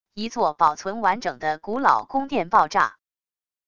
一座保存完整的古老宫殿爆炸wav音频